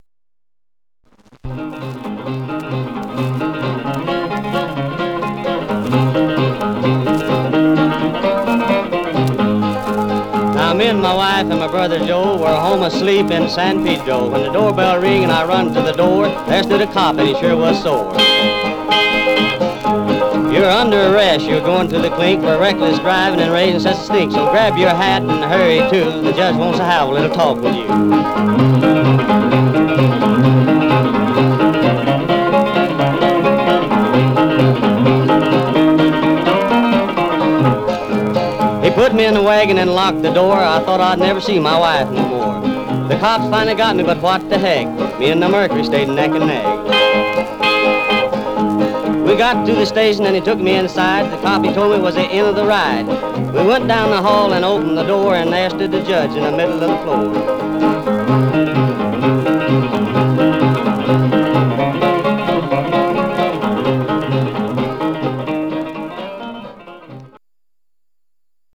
Stereo/mono Mono
Country